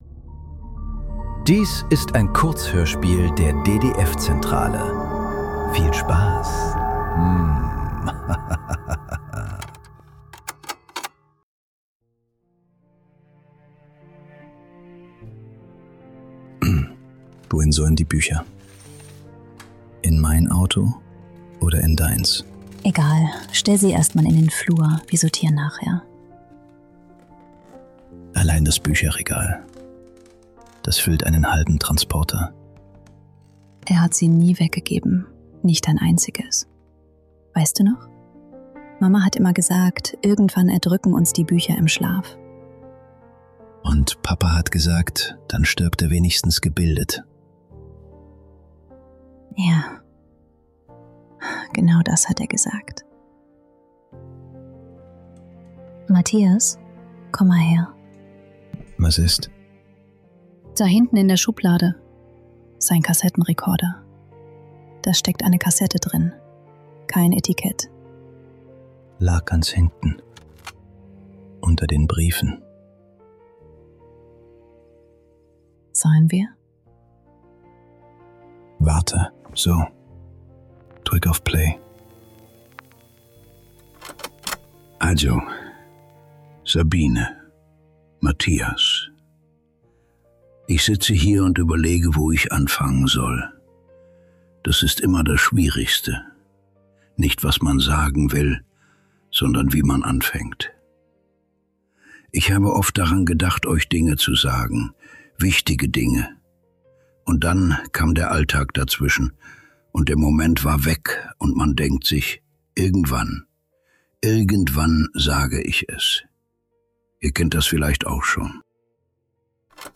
Was ich euch sagen wollte ~ Nachklang. Kurzhörspiele.